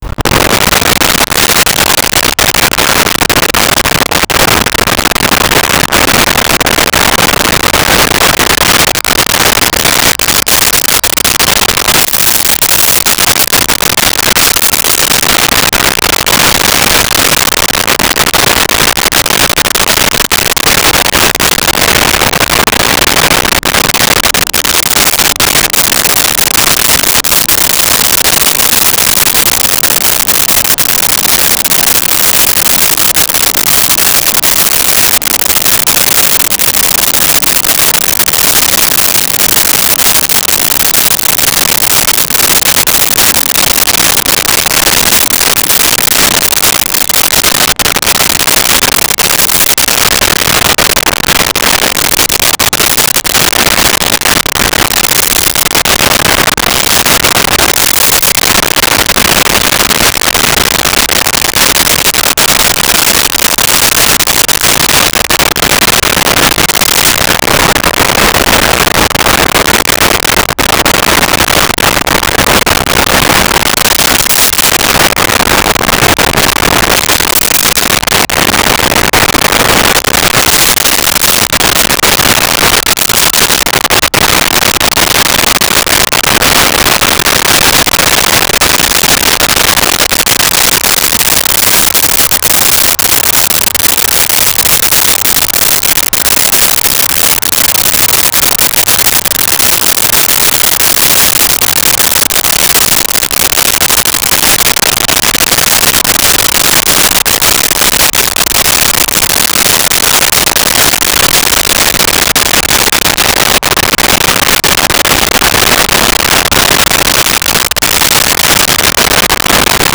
Subway Station Exterior
Subway Station Exterior.wav